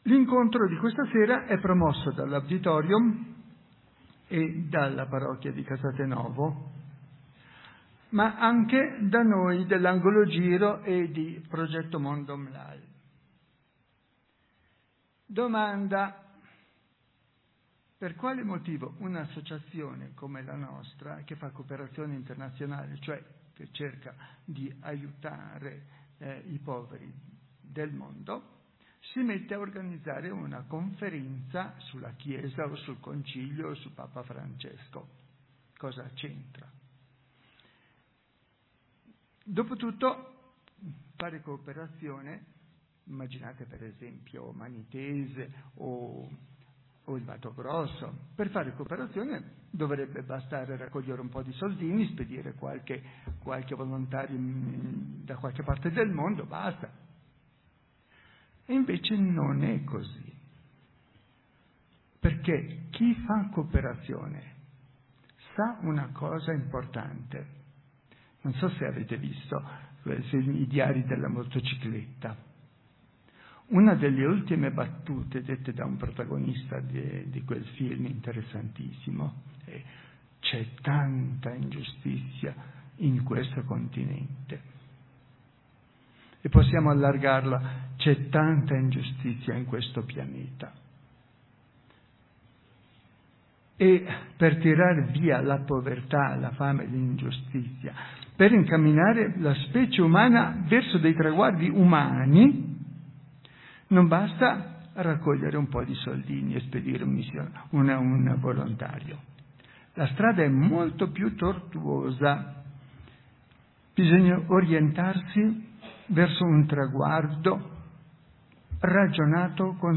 Incontri per ascoltare, approfondire, riflettere